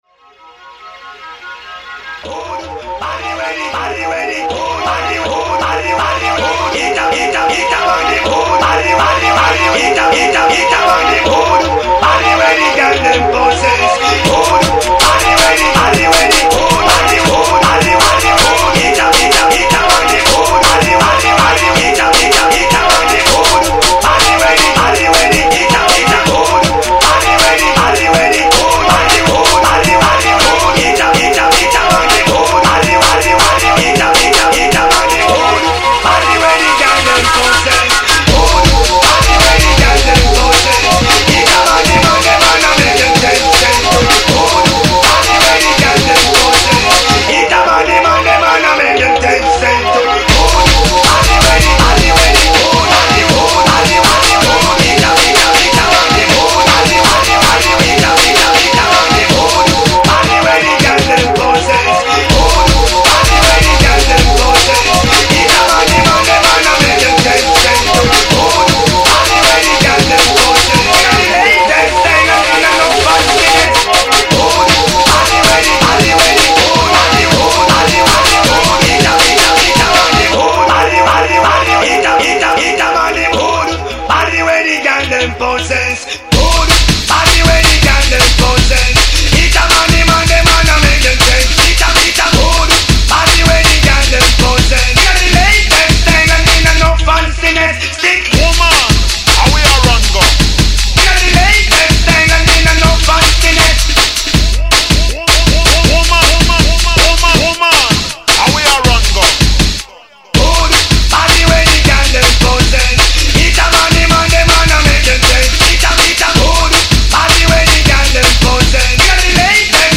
Previously unreleased Dubplate
Exclusive VIP mix